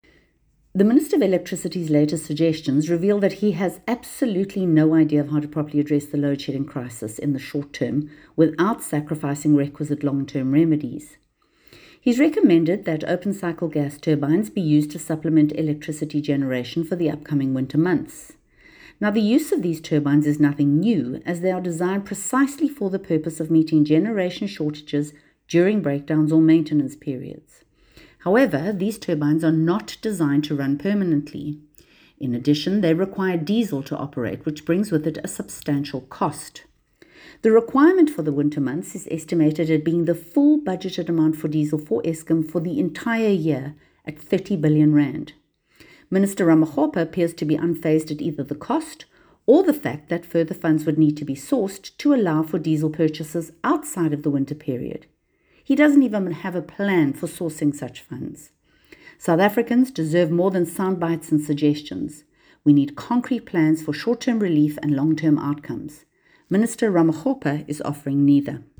soundbite by Samantha Graham-Maré MP.